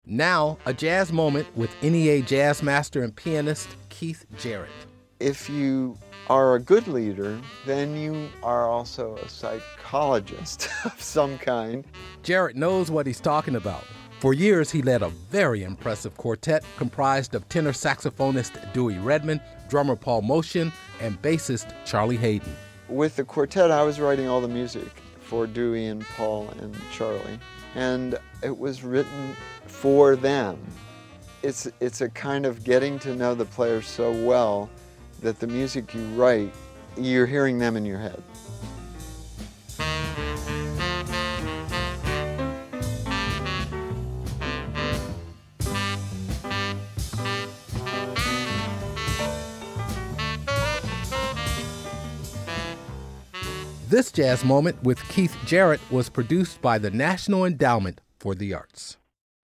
Excerpt of “Forget Your Memories” from the album Birth, composed by Keith Jarrett and performed by the Jarrett American Quartet, used courtesy of Atlantic/Rhino Records and by permission of Kundalini Music. (BMI)